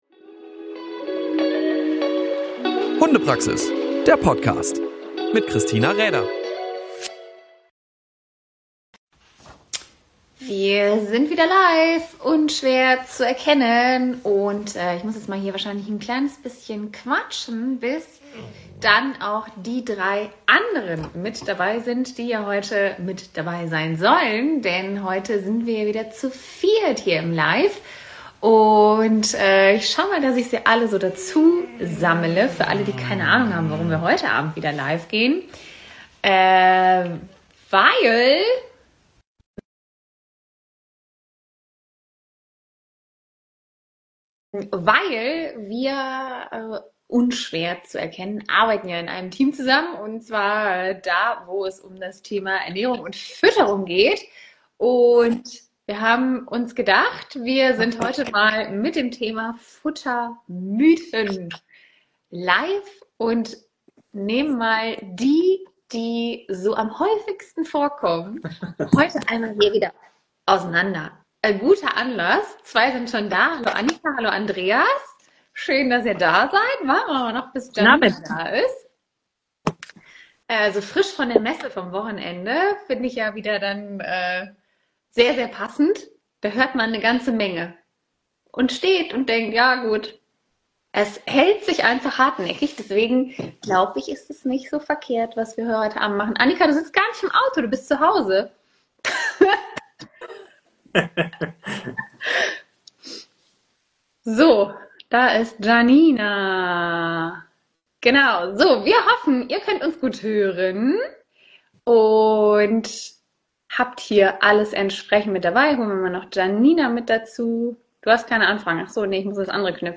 Unser Insta-Live ist nun auch noch in den Podcast gewandert!